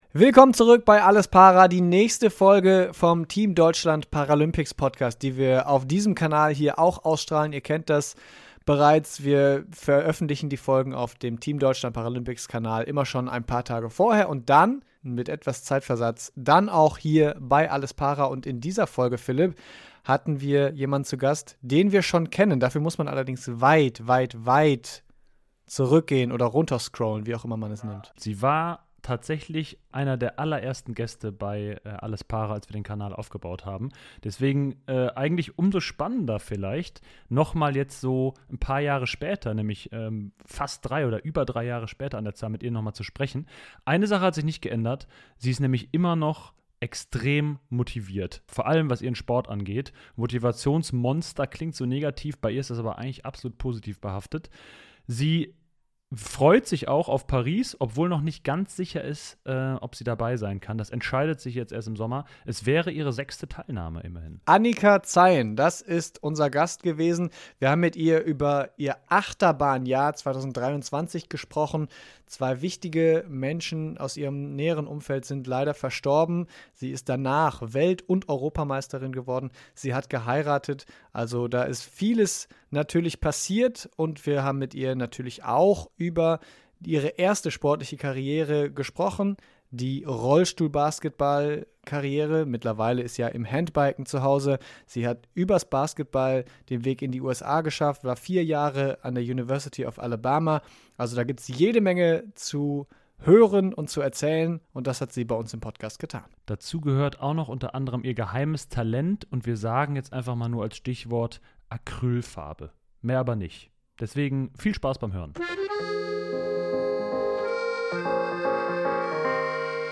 Im Team Deutschland Paralympics Podcast erzählt sie, warum sie keine Basketball-Karriere in den USA angestrebt hat, warum 2023 für sie wie eine Achterbahnfahrt war und warum Radsport auch ein Teamsport ist. Aufgrund von technischen Problemen ist der Ton bei Annika in der ersten Viertelstunde leider nicht in gewohnter Qualität.